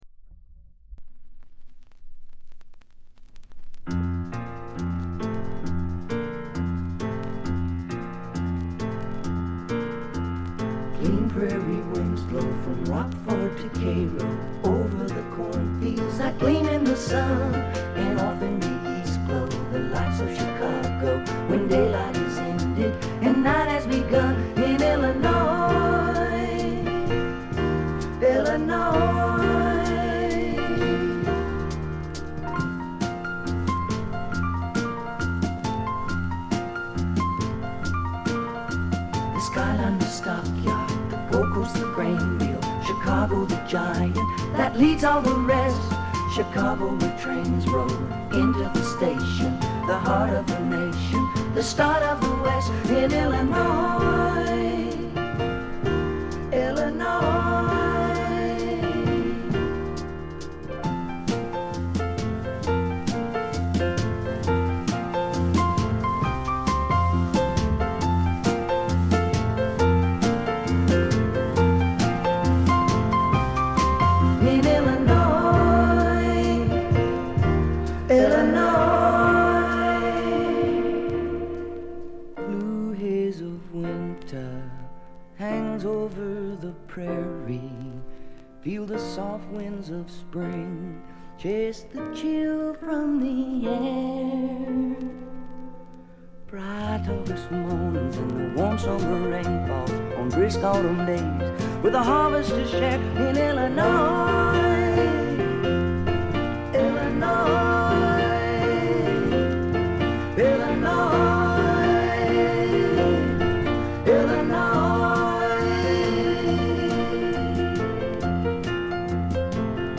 ところどころでチリプチ。
試聴曲は現品からの取り込み音源です。